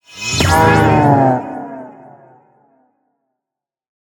哞菇在转变颜色时随机播放这些音效
Minecraft_mooshroom_convert1.mp3